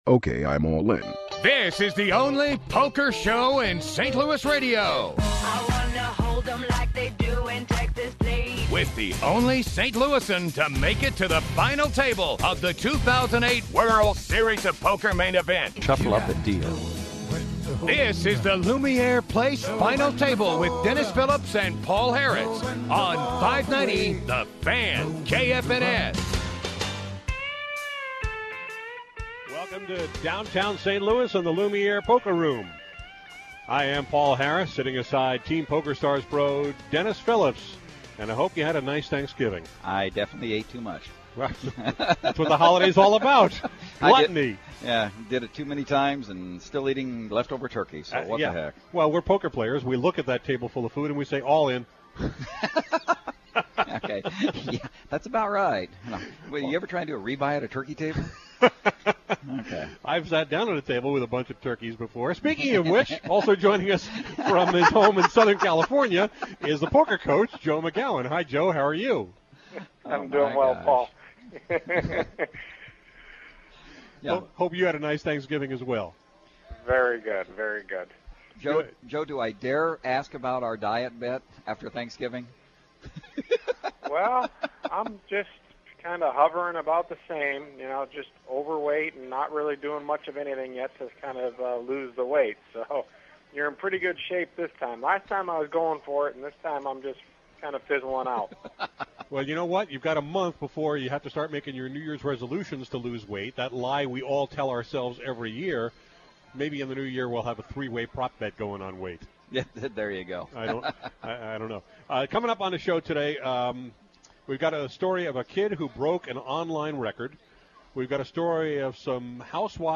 poker radio show